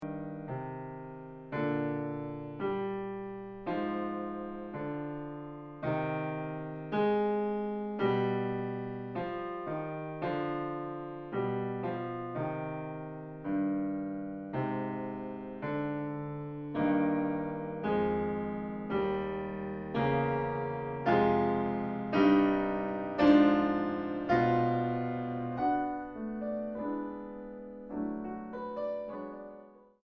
Lento 1.20